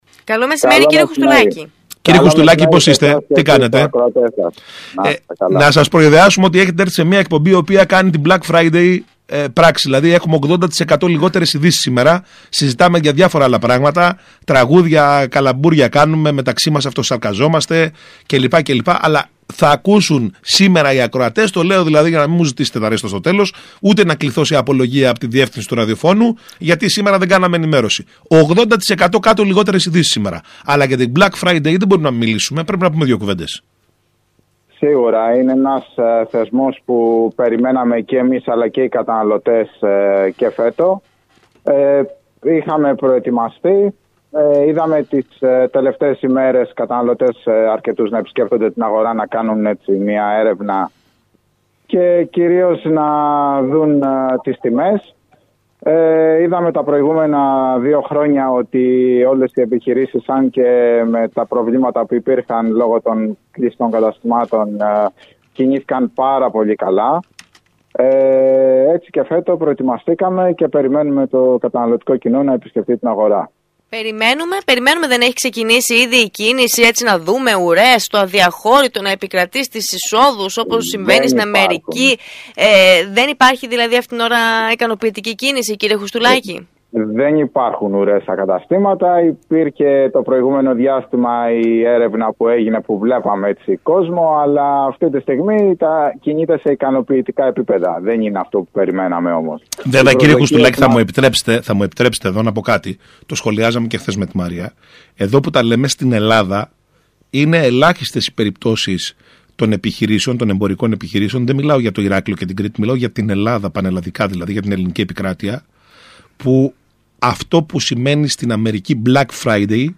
μιλώντας στον ΣΚΑΪ Κρήτης 92,1